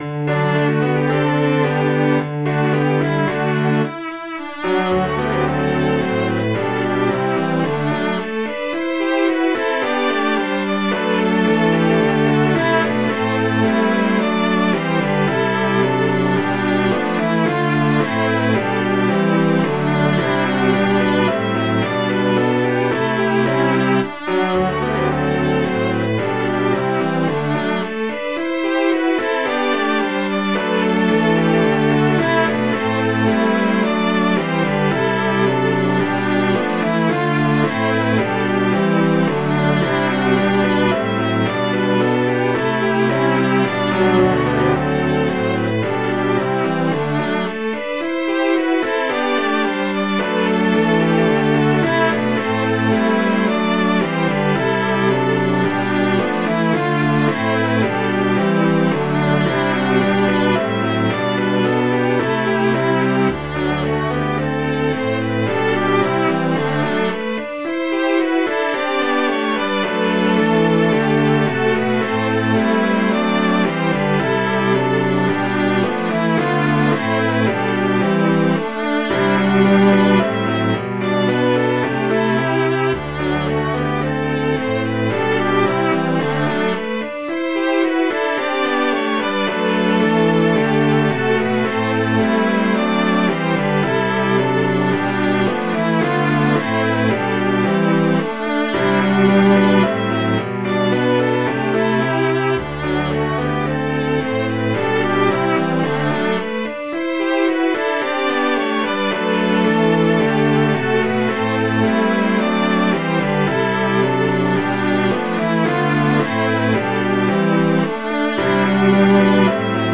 Midi File, Lyrics and Information to As I Walked Through the Meadow